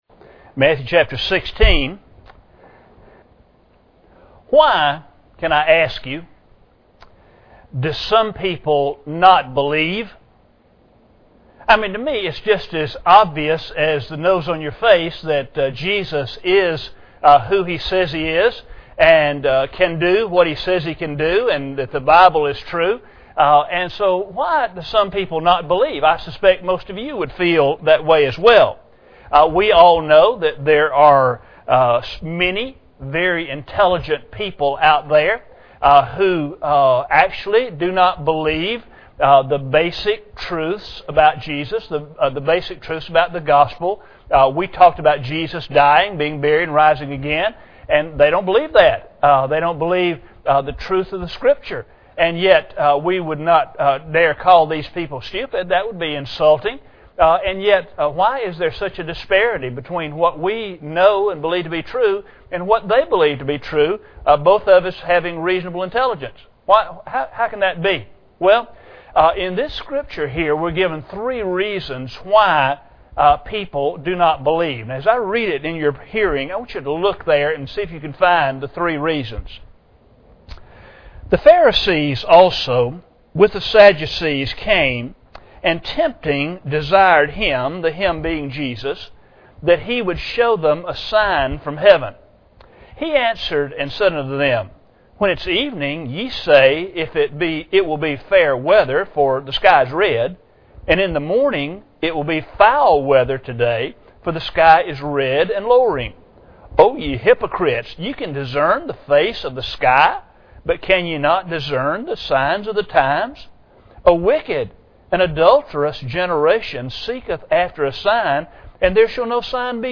Matthew 16:1-17 Service Type: Sunday Morning Bible Text